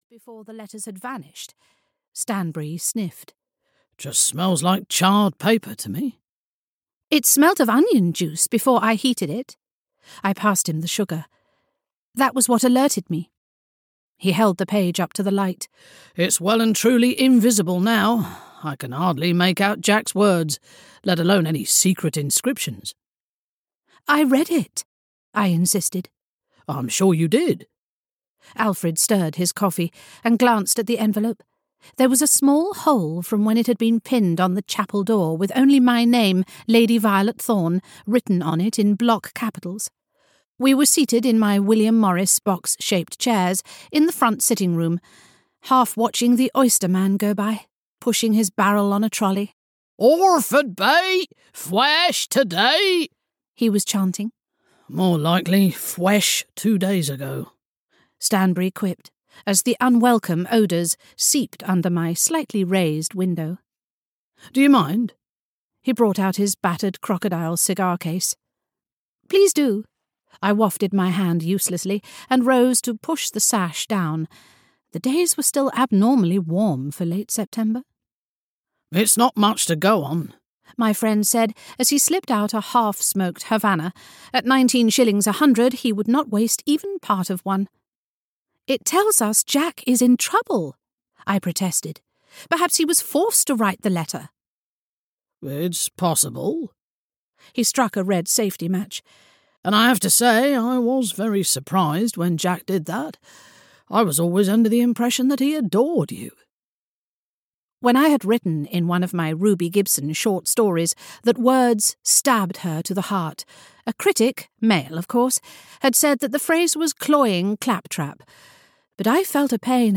Audiobook The Montford Maniac written by M.R.C. Kasasian.
Ukázka z knihy